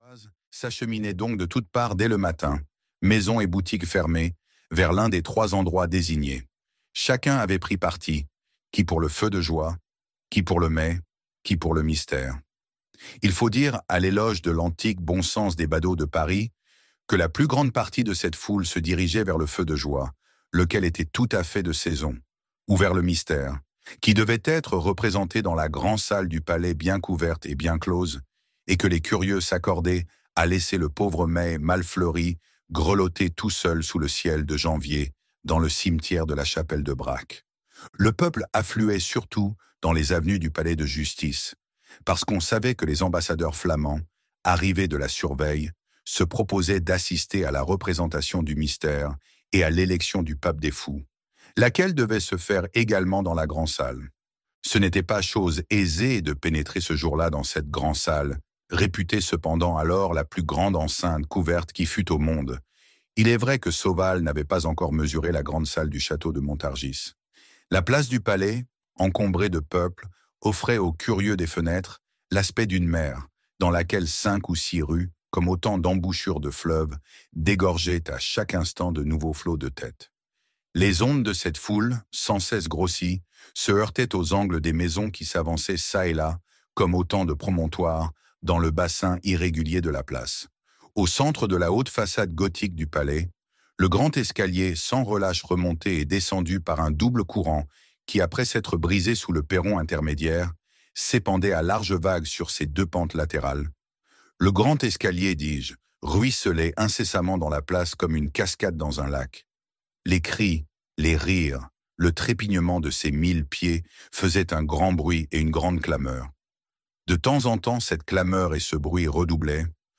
Notre-Dame de Paris - Livre Audio